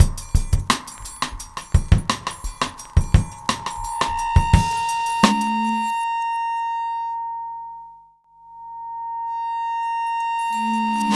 Index of /90_sSampleCDs/Best Service ProSamples vol.40 - Breakbeat 2 [AKAI] 1CD/Partition B/MEANGREEN086